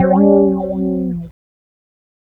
Bass Lick 35-07.wav